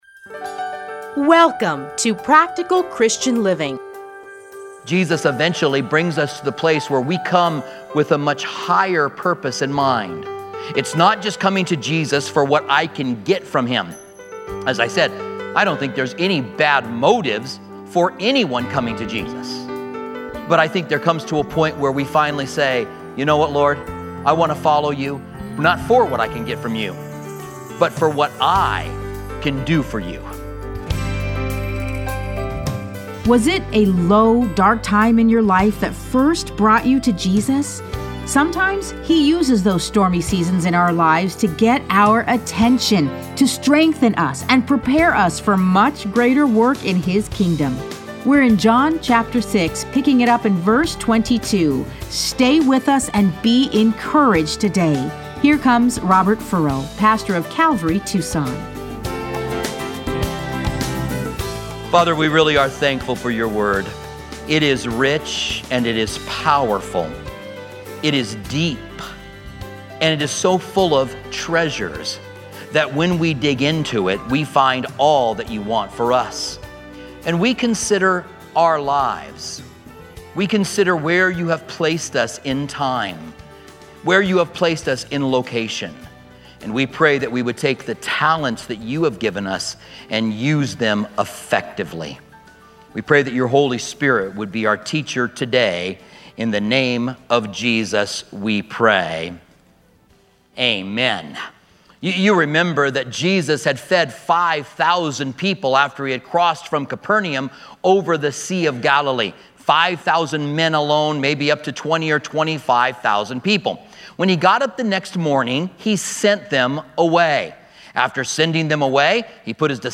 Listen to a teaching from John 6:22-40.